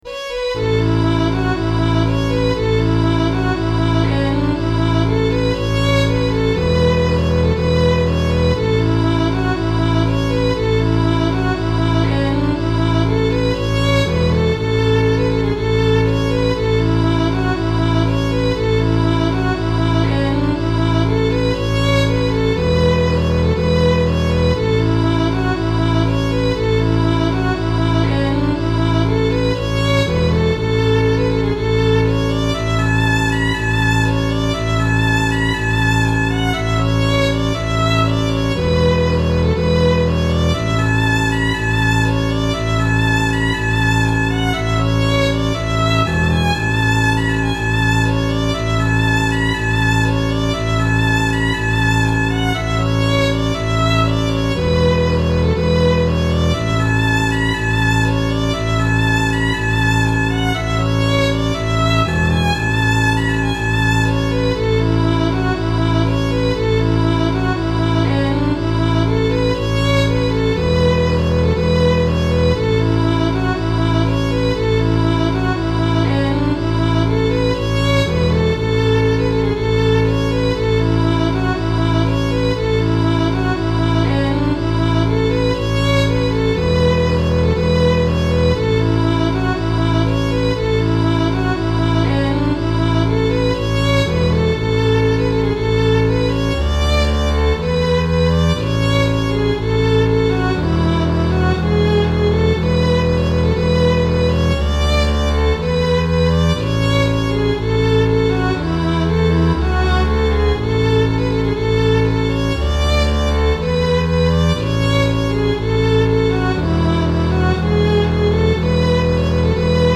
I used a lot of grace notes in this one.  I can pretty much play the melody, but I'll have to work on the grace notes.